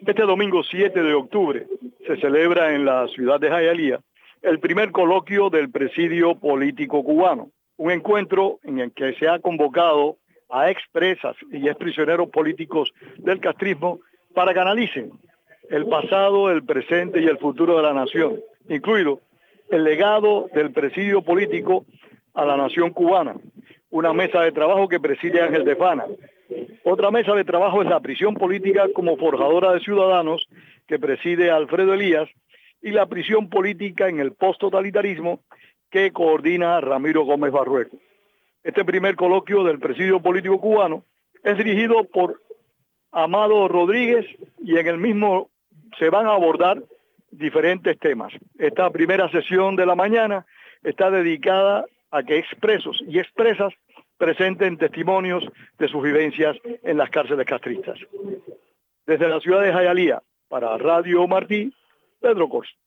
reporta desde el evento